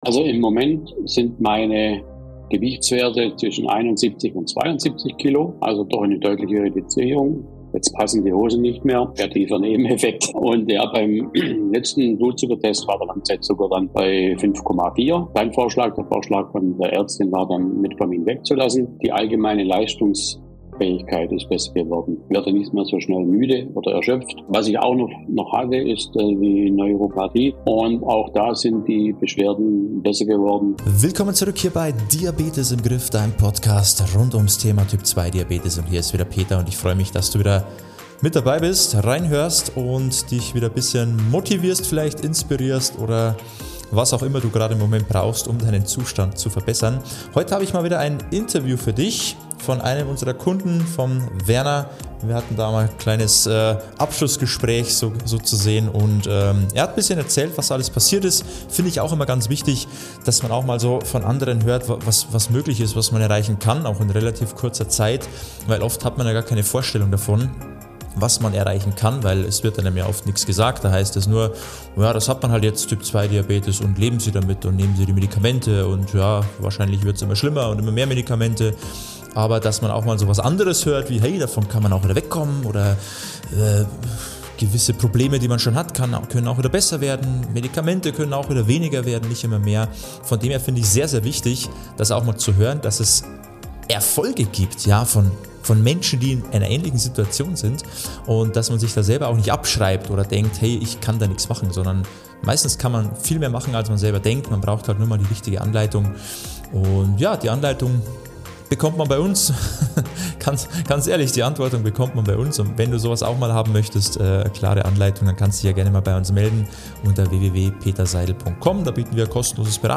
In diesem spannenden Interview bekommst du eine ordentliche Portion Motivation.